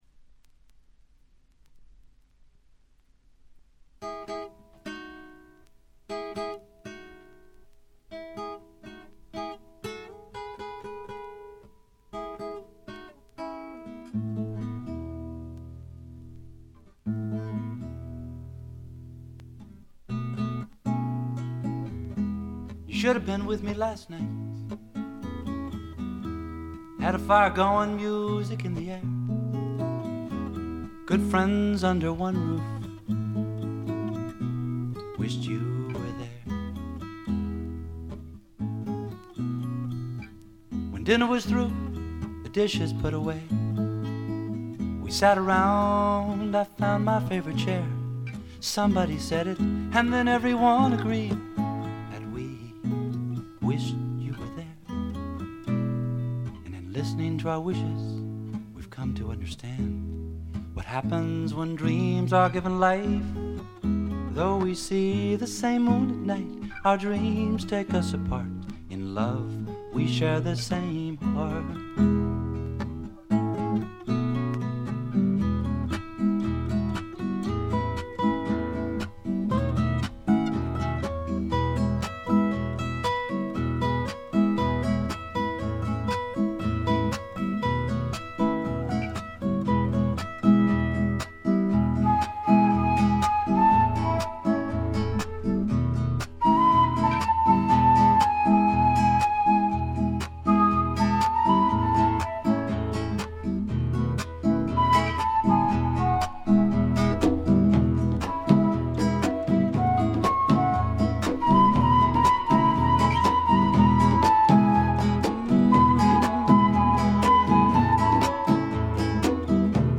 ところどころで軽微なチリプチ程度。
そこここにハワイ産アコースティック・グルーヴのような涼やかでちょっとトロピカルな気分が漂うあたりもいとをかし。
試聴曲は現品からの取り込み音源です。